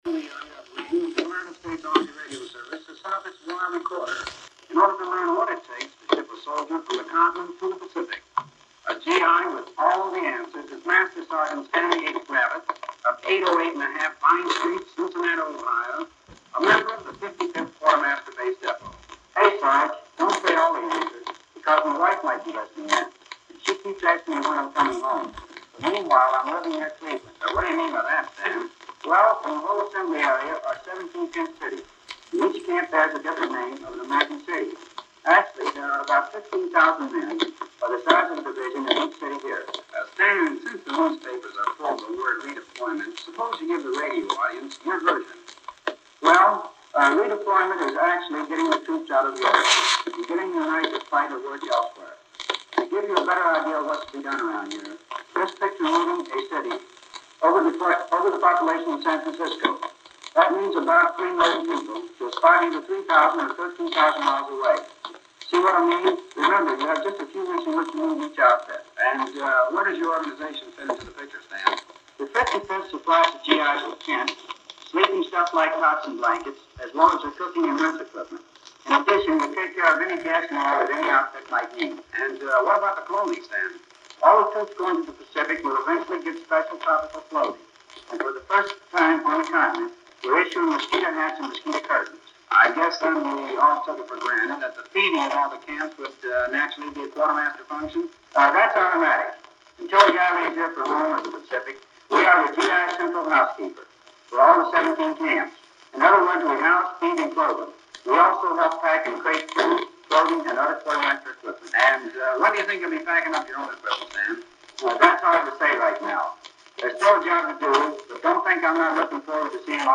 by U.S. Army Radio Service via WCPO Radio 78 RPM album